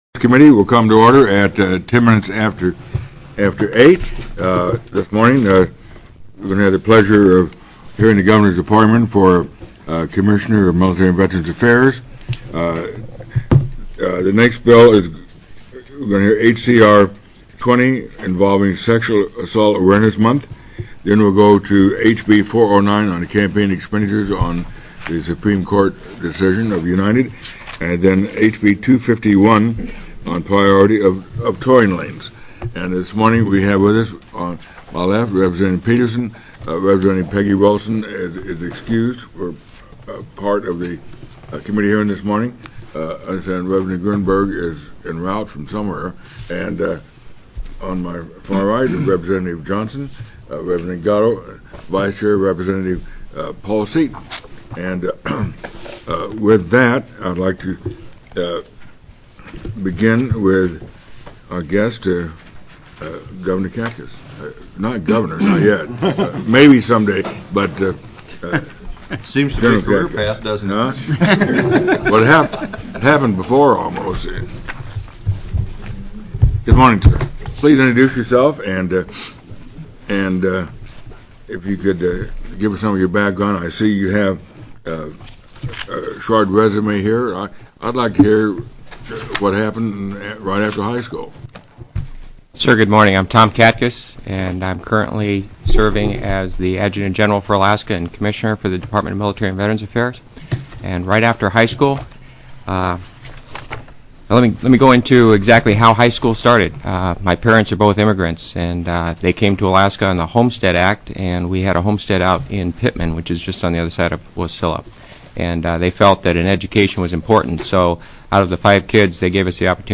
Confirmation Hearing(s)
TELECONFERENCED